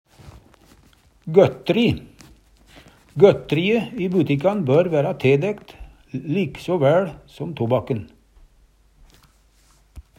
gøttri - Numedalsmål (en-US)